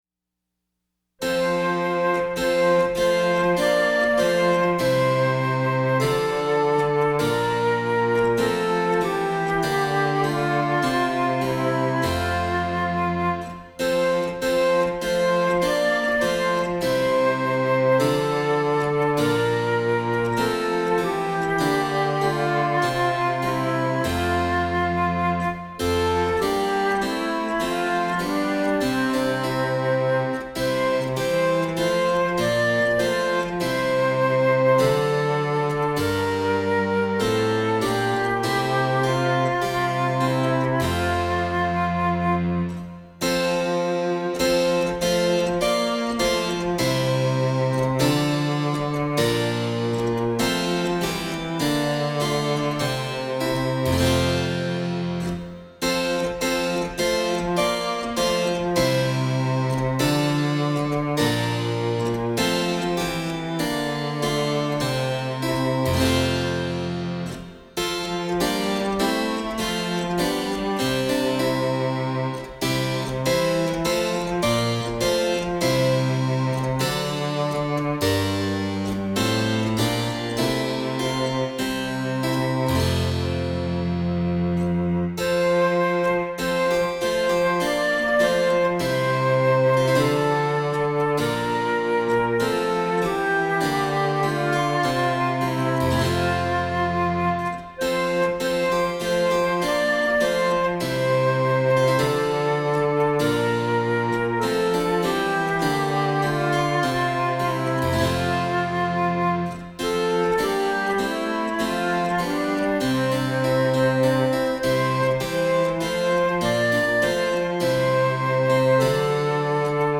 Keyboards and synthesizers.